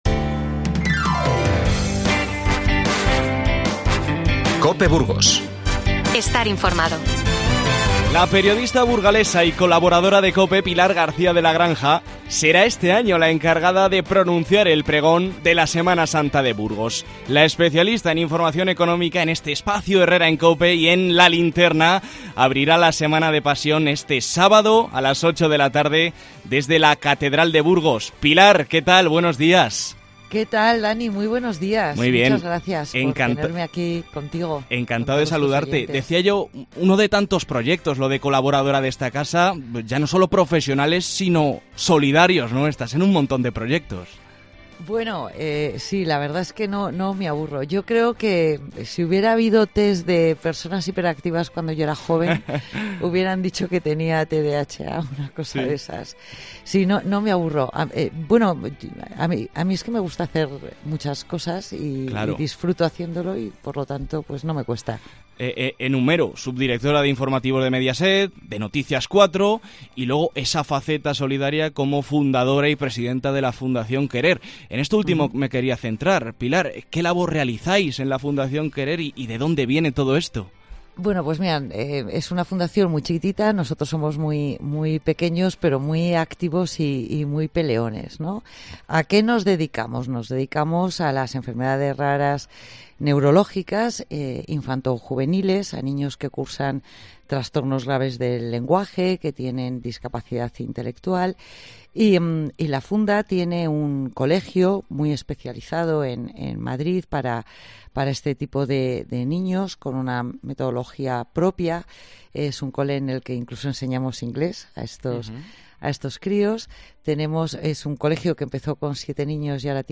Entrevista a Pilar García de la Granja, antes de su pregón de la Semana Santa de Burgos 2024